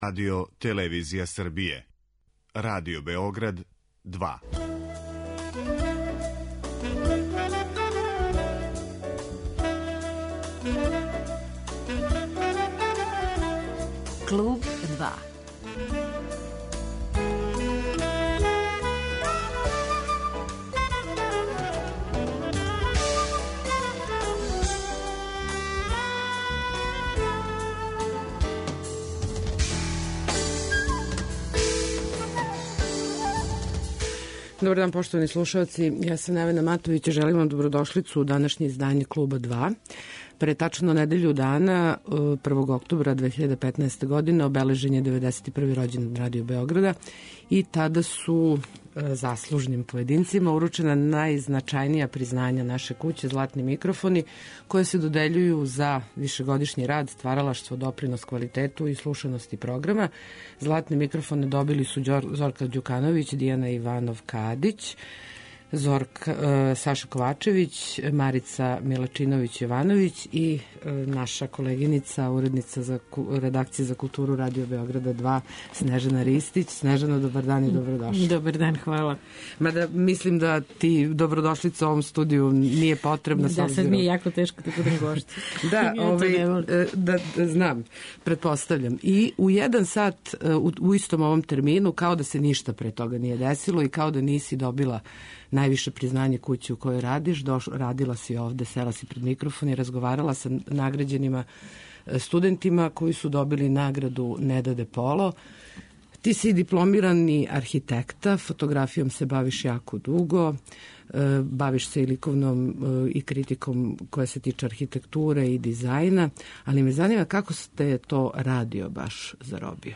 Гошћа